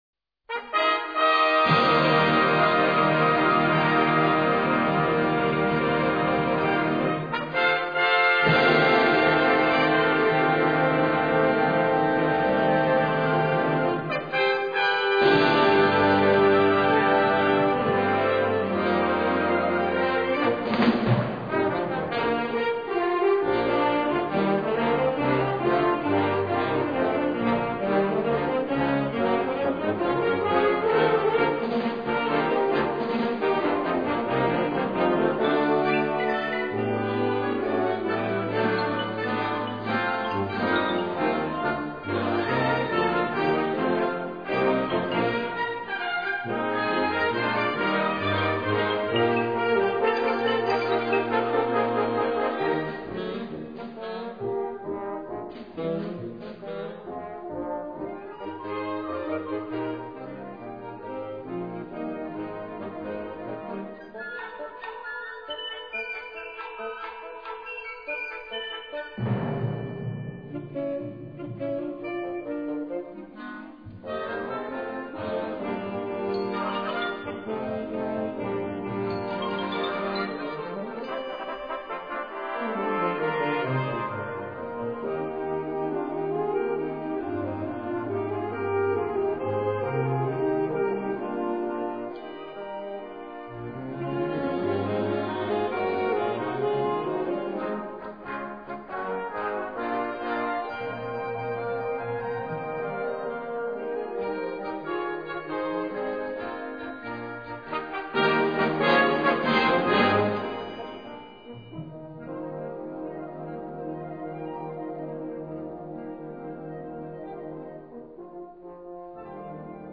Sous-catégorie Musique contemporaine (1945-présent)
Instrumentation Ha (orchestre d'harmonie)